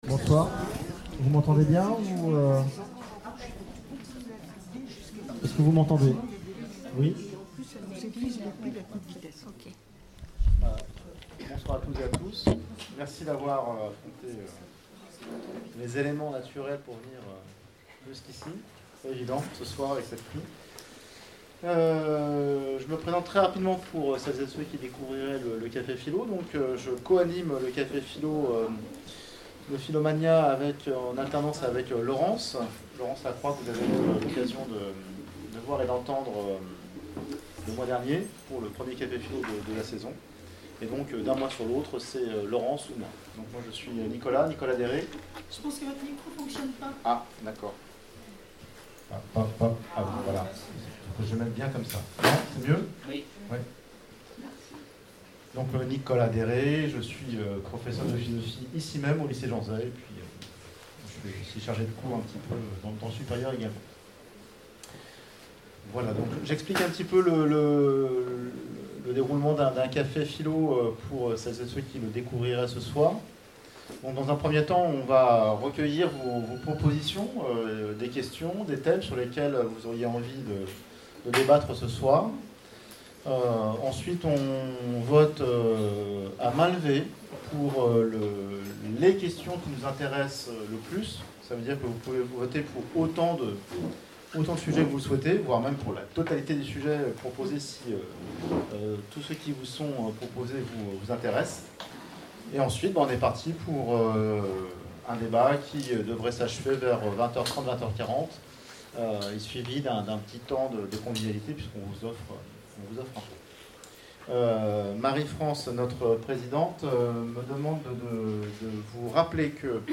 Conférences et cafés-philo, Orléans